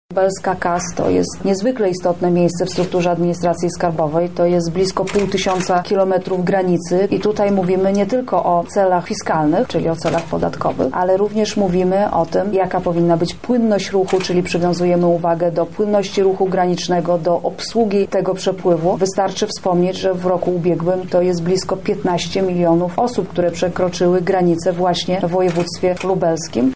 Minister -mówi minister finansów Teresa Czerwińska.